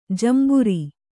♪ jamburi